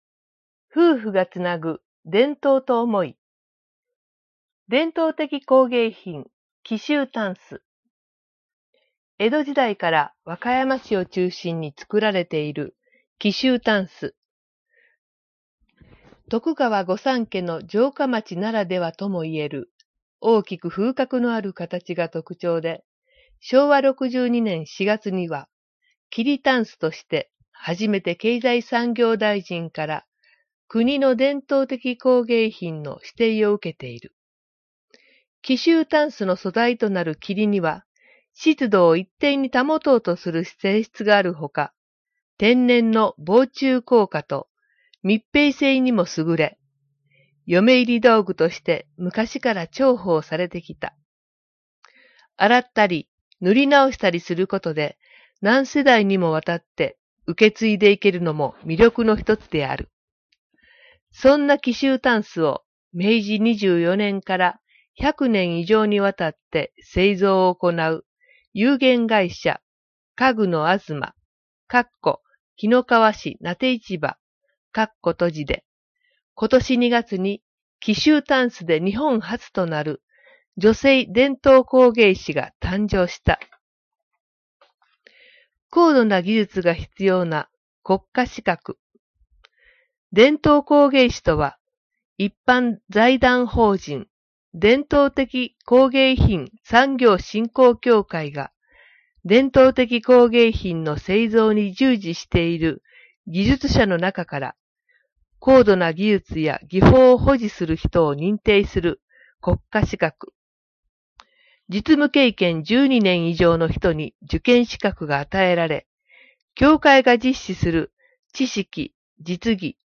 「広報紀の川」の音声版を、MP3形式の音声ファイルでダウンロードしていただけます。